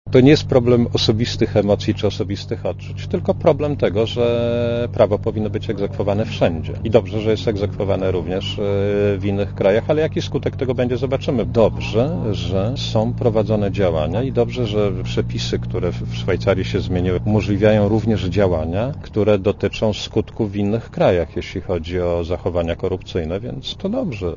Posłuchaj komentarza Marka Balickiego